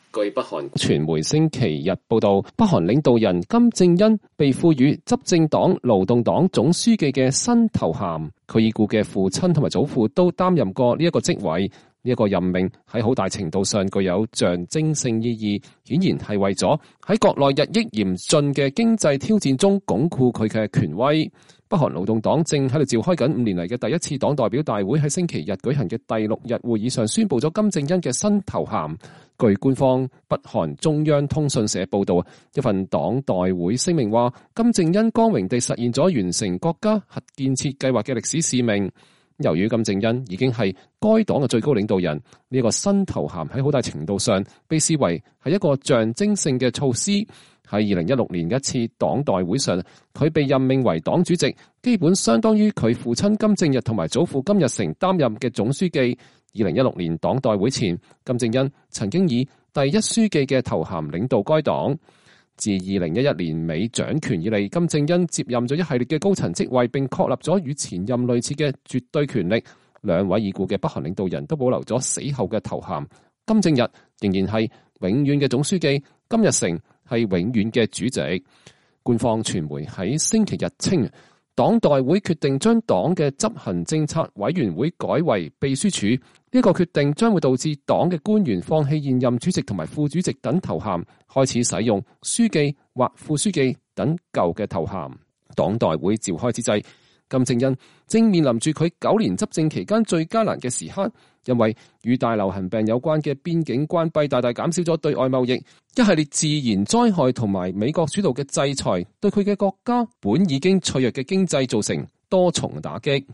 北韓最高領導人金正恩在第八屆勞動黨代表大會上講話。